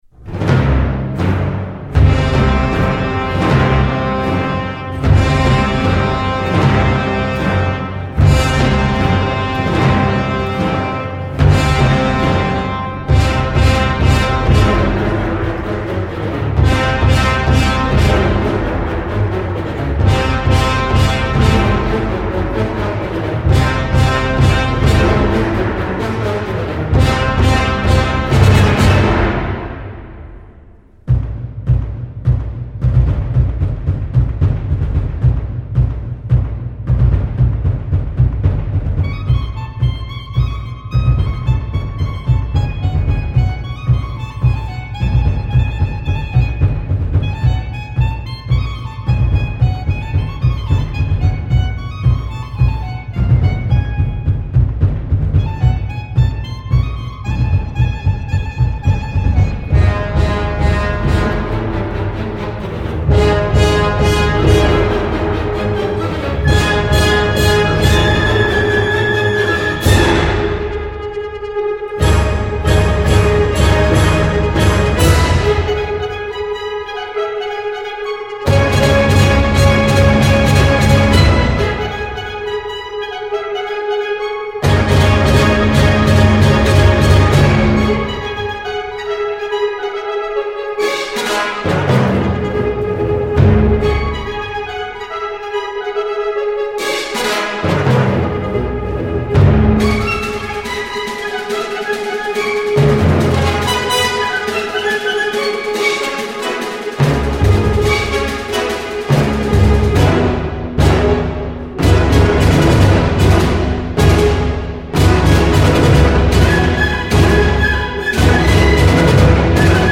今年的珍藏版纪念CD两张一套，均为精选的发烧录音，流行、古典、民乐、爵士等各种音
为低音质MP3